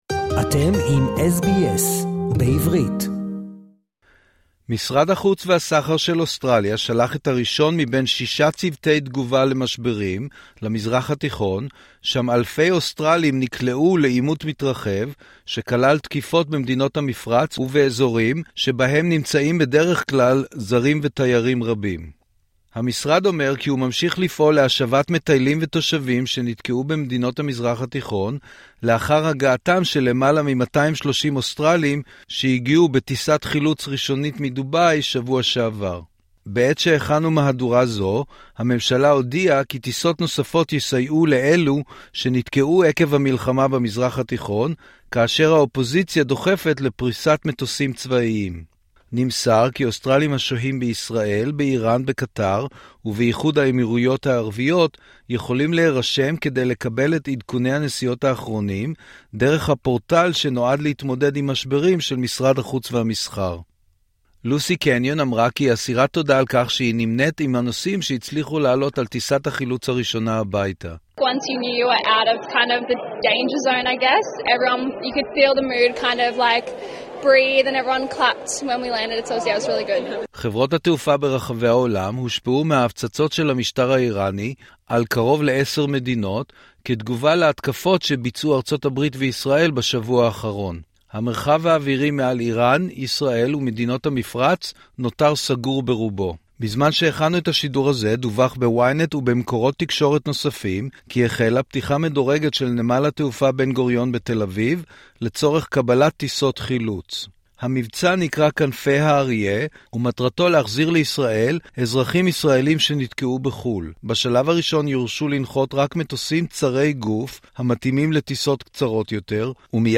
משרד החוץ והסחר של אוסטרליה שלח את הראשון מבין שישה צוותי תגובה למשברים למזרח התיכון, שם אלפי אוסטרלים נקלעו לעימות מתרחב שכלל תקיפות במדינות המפרץ ובאזורים המאוכלסים בצפיפות על-ידי זרים ותיירים. נמסר כי אוסטרלים השוהים בישראל, באיראן, בקטר ובאיחוד האמירויות הערביות יכולים להירשם כדי לקבל את עדכוני הנסיעות האחרונים דרך הפורטל שנועד להתמודד עם משברים של משרד החוץ והמסחר. שוחחנו עם שתי נוסעות שתוכניות הנסיעה שלהם שובשו.